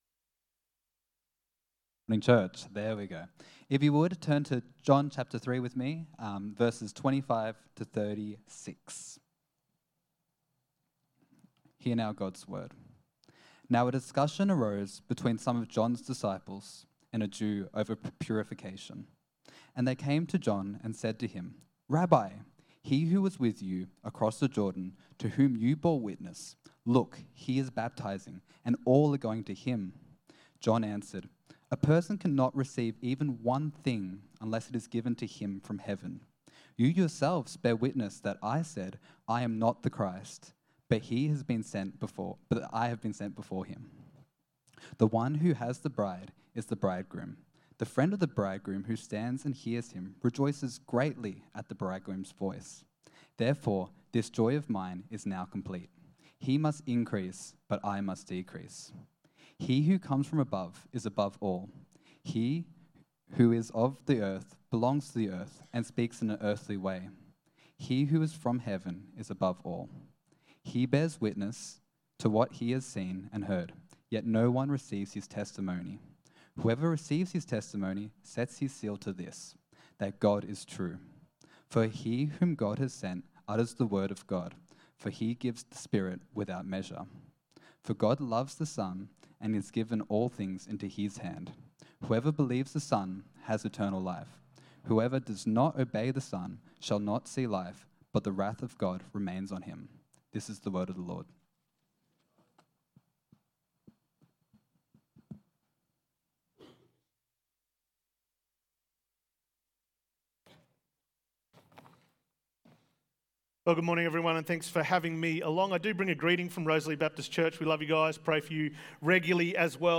Sermons | Coomera Baptist Church